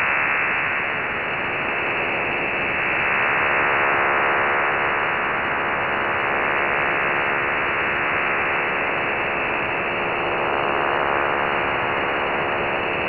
Псевдо OFDM